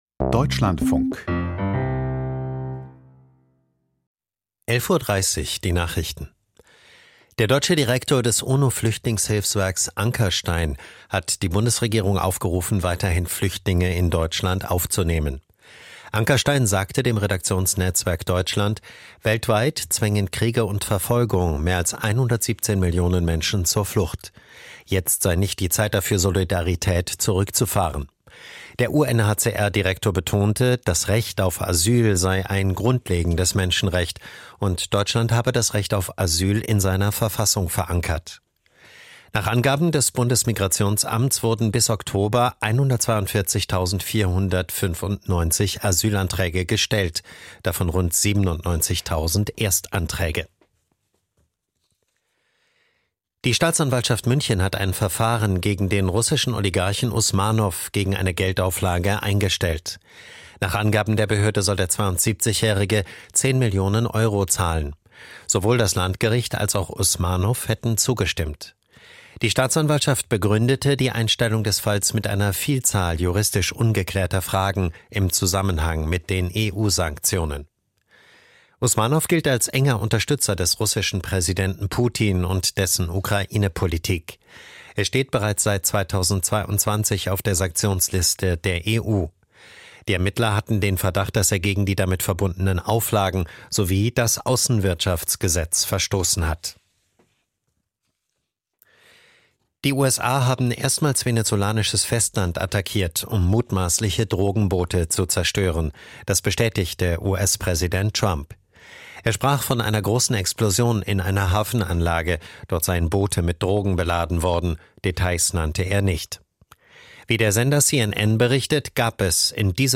Die Nachrichten vom 30.12.2025, 11:30 Uhr